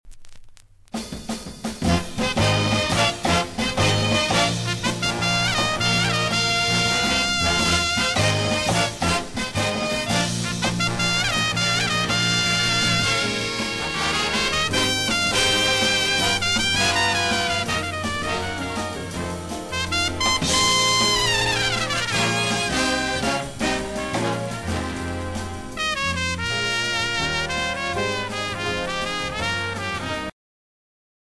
Jazz
trumpet
vibes